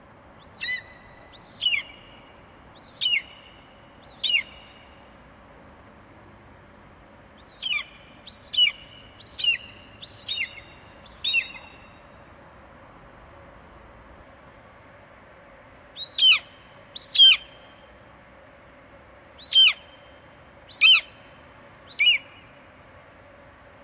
eagle
Category 🗣 Voices